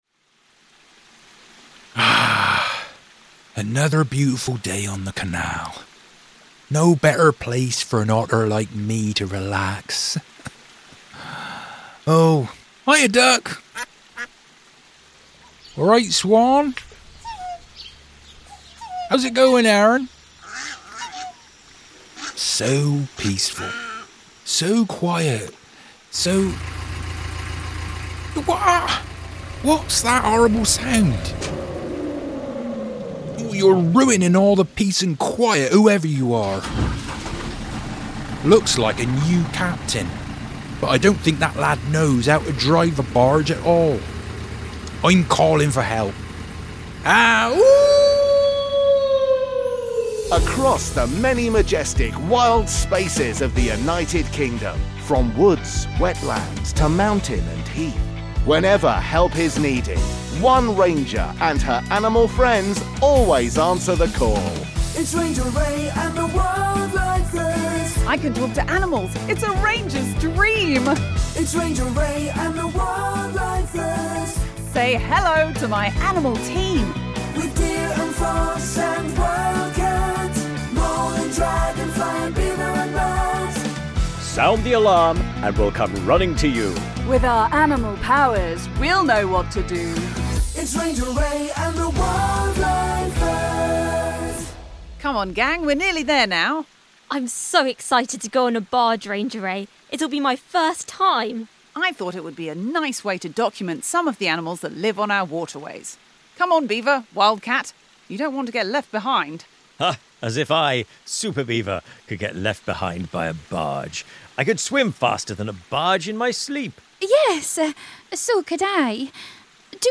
All other characters played by members of the ensemble.